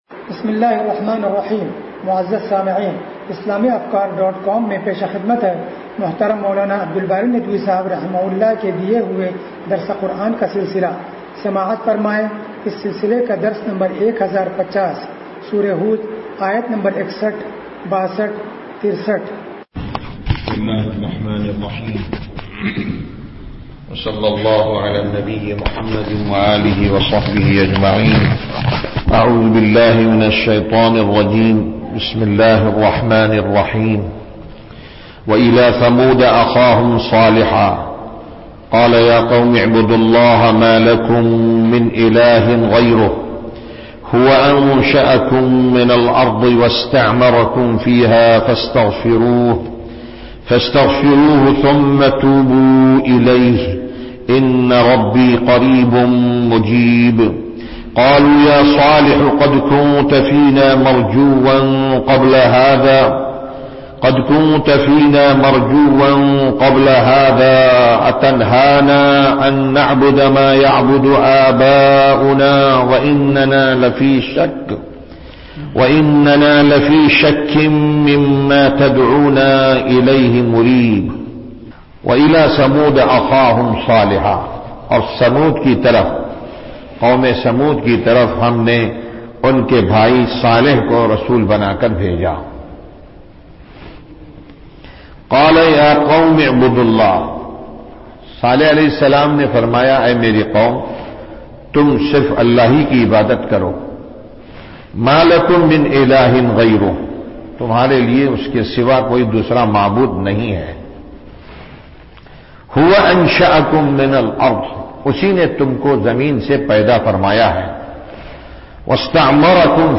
درس قرآن نمبر 1050